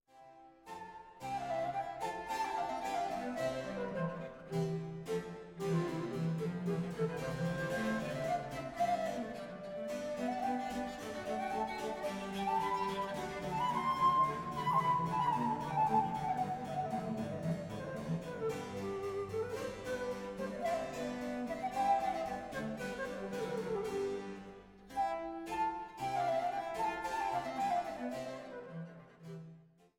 Sopran
Traversflöte
Blockflöte
Viola da gamba
Cembalo
Adagio